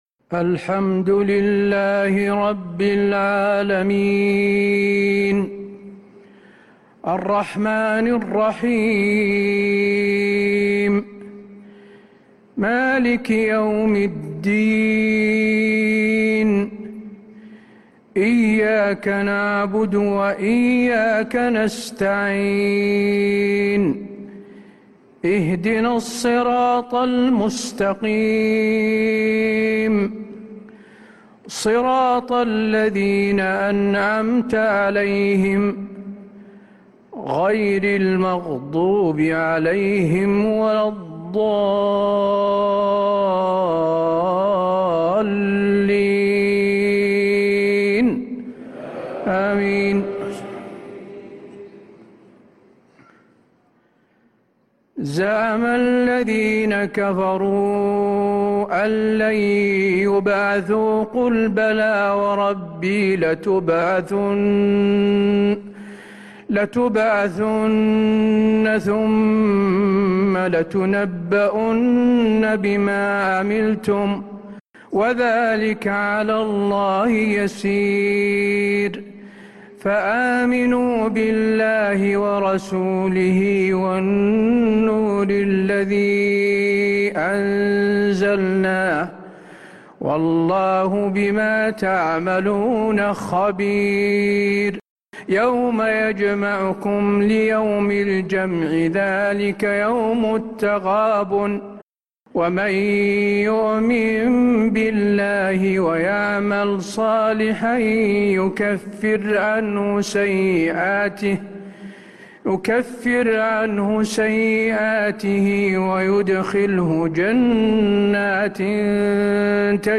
صلاة العشاء للقارئ حسين آل الشيخ 11 ذو القعدة 1445 هـ
تِلَاوَات الْحَرَمَيْن .